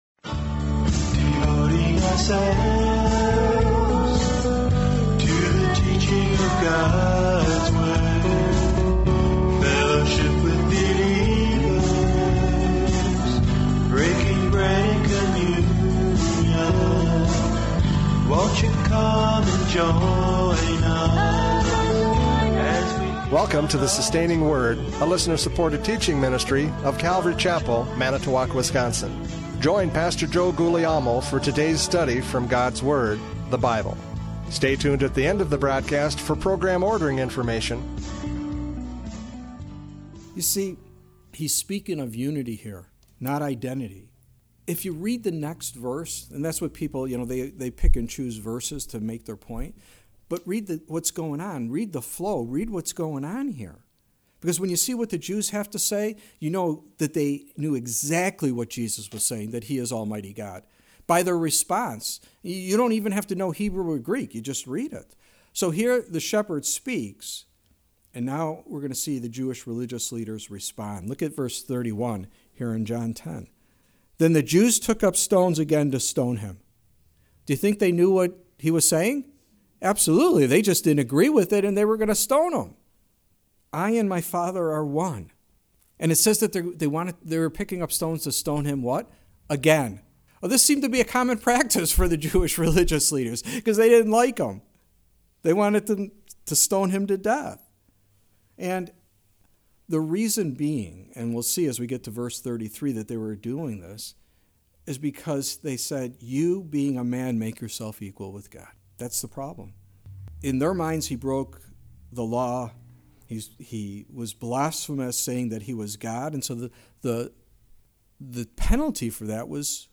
John 10:22-42 Service Type: Radio Programs « John 10:22-42 The Good Shepherd Speaks!